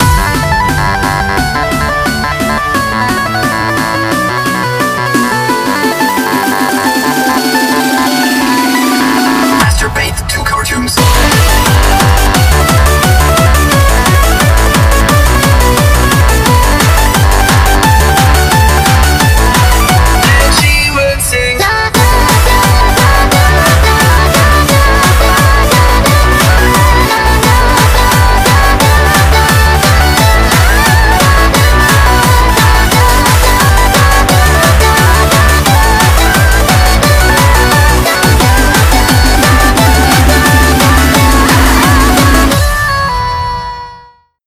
goddess_pr0n_anthem.mp3